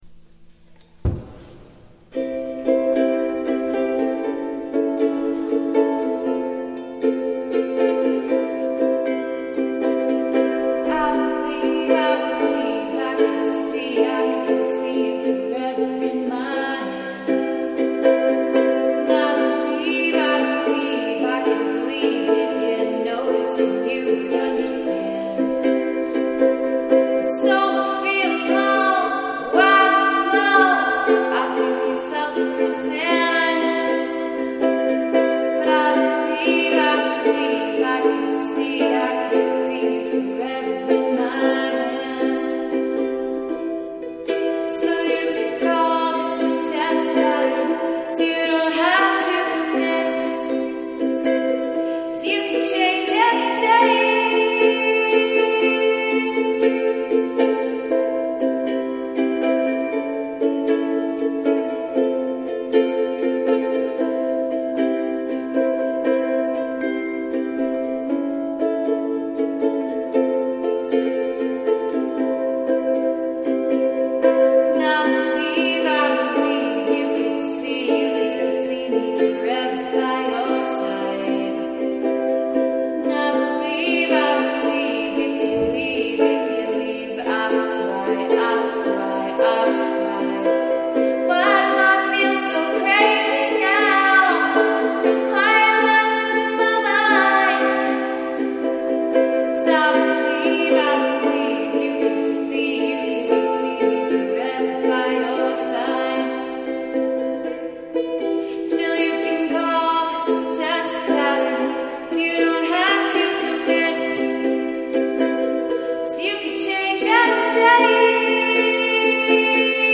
CHECK OUT HER COVER